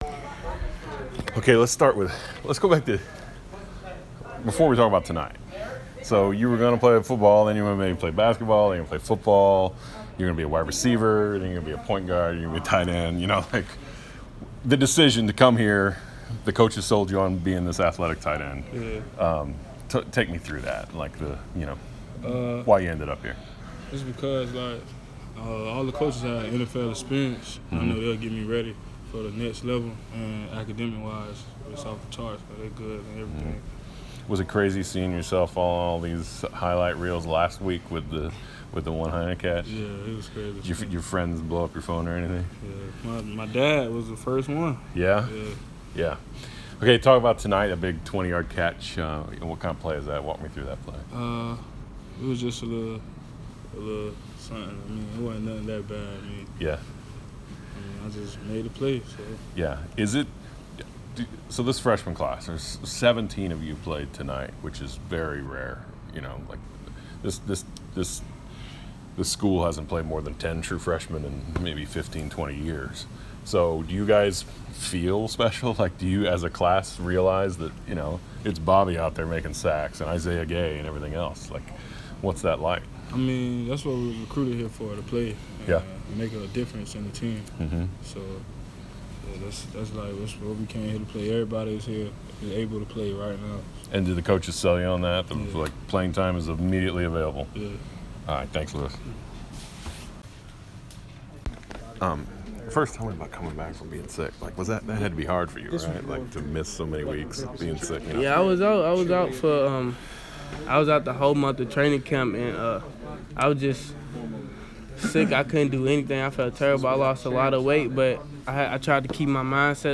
Player Interviews - Western Kentucky